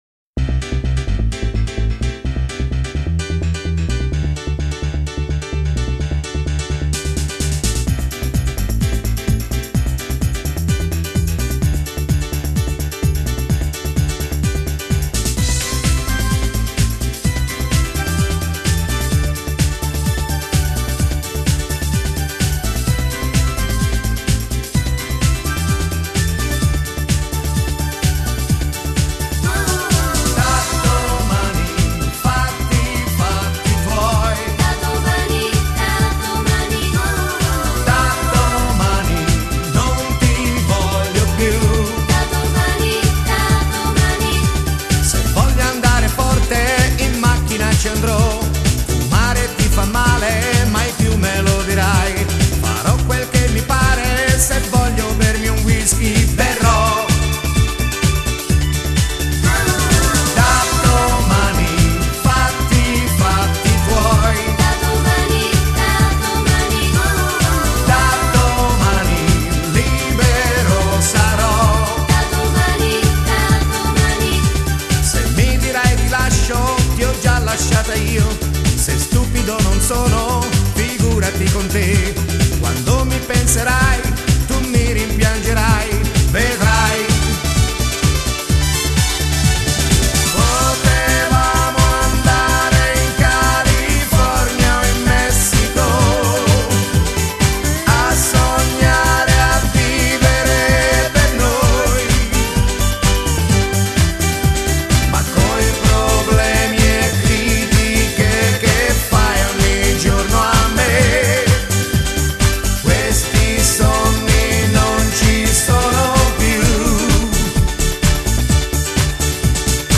Genere: Disco Dance